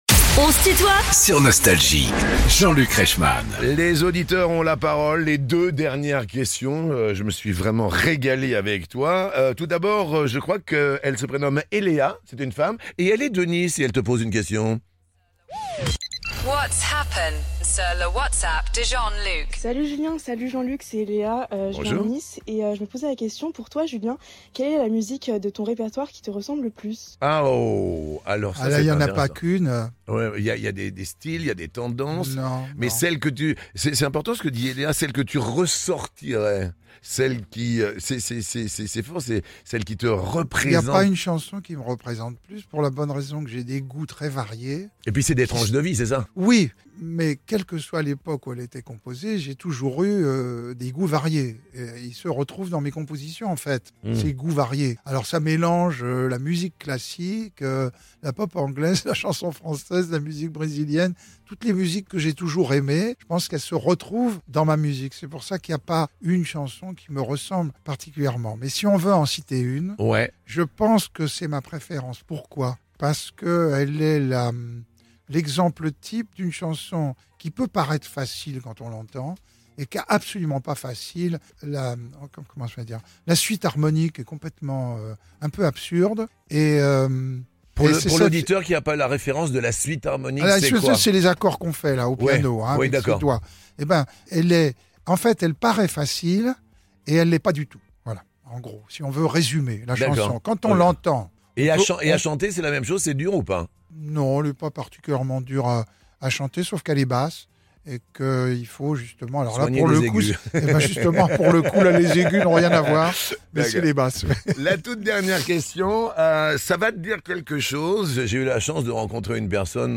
What's happen : Julien Clerc répond aux questions des auditeurs Nostalgie
Les interviews